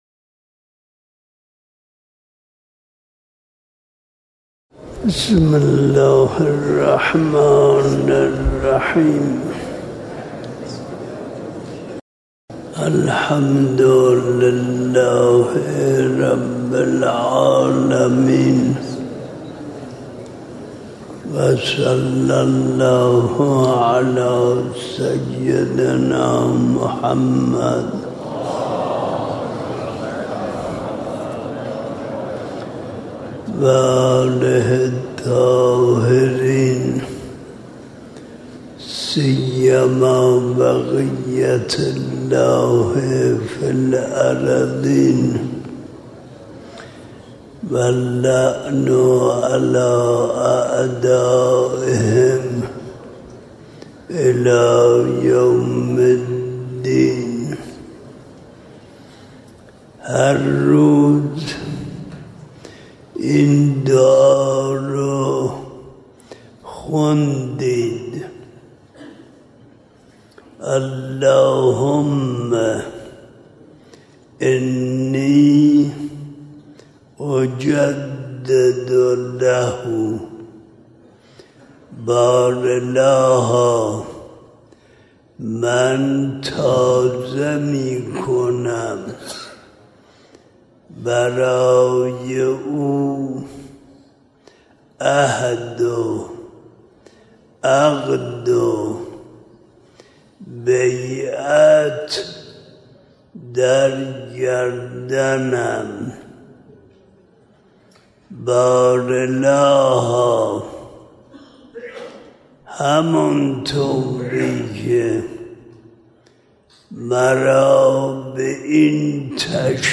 بیانات معظم له به مناسبت نیمه شعبان 1436 | سایت رسمی دفتر حضرت آيت الله العظمى وحيد خراسانى